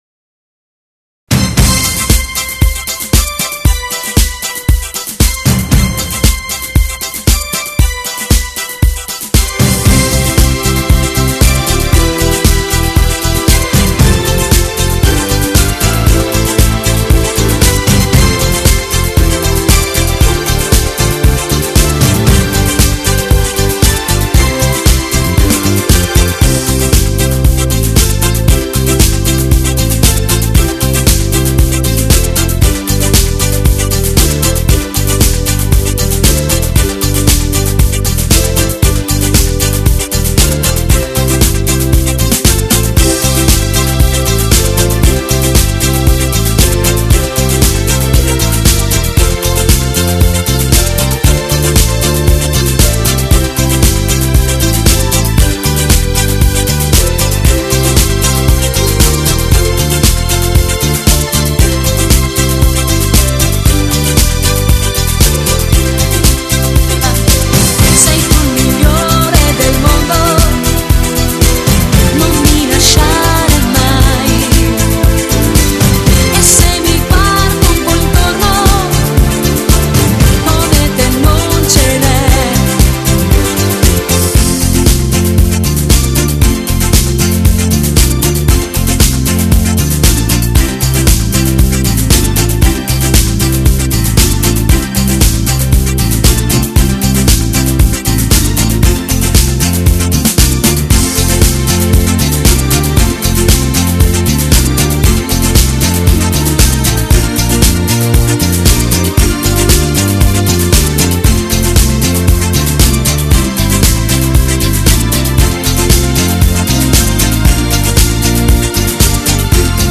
Genere: Allegro
Scarica la Base Mp3 (3,55 MB)